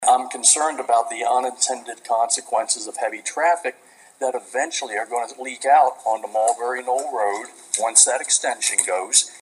The Sussex County Planning and Zoning Commission held a lengthy public hearing with packed Council Chambers late yesterday afternoon for a Change of Zone request, #2037, from Mulberry Knoll Associates, LLC.